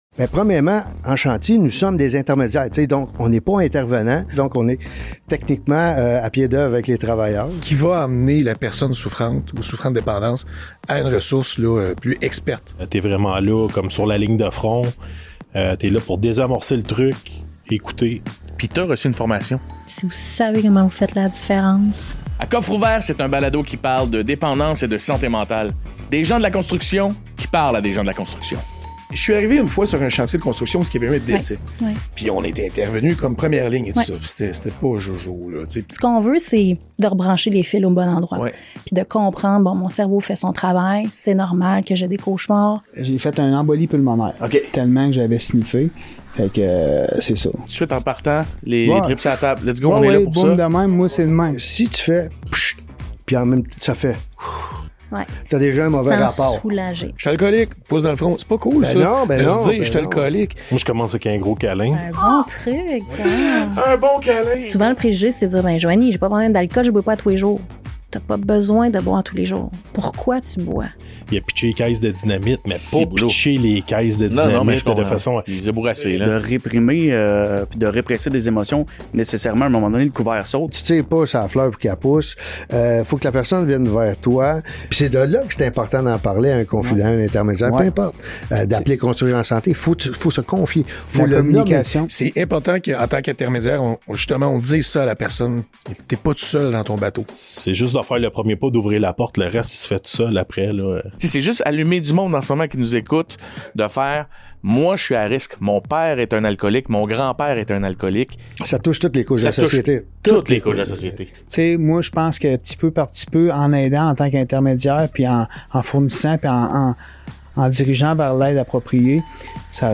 Animé par l’humoriste, animateur et comédien Jonathan Roberge, ce balado met en lumière des Intermédiaires et des spécialistes qui abordent sans tabous les problèmes de dépendance et de santé mentale, des enjeux qui sont loin d’être propres à l’industrie de la construction.